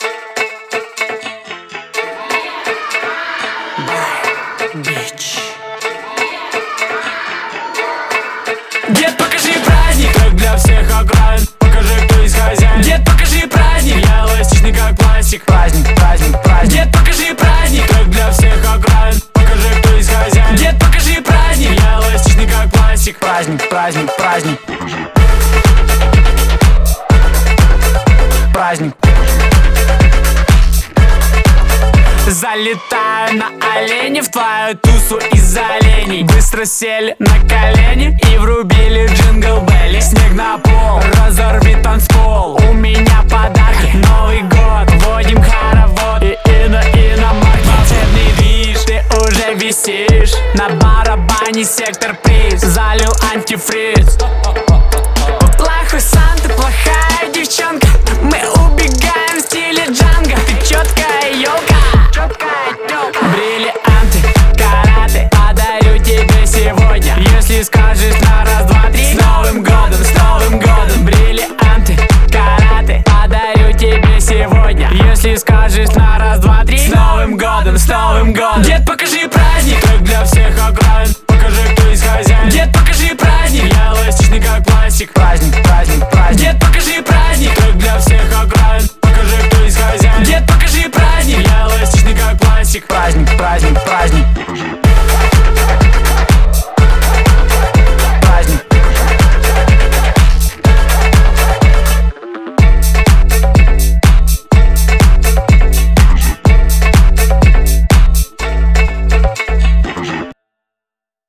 относится к жанру поп
обладает ярким, жизнерадостным настроением